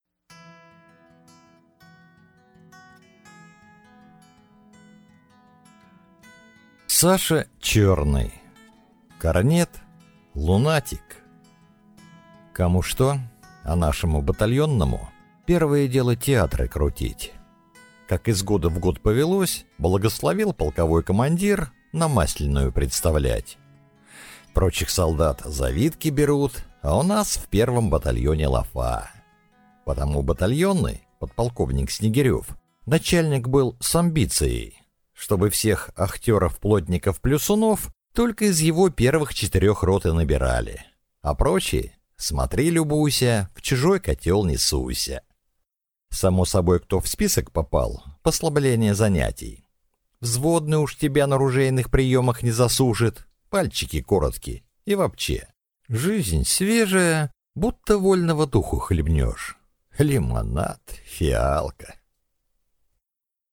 Аудиокнига Корнет-лунатик | Библиотека аудиокниг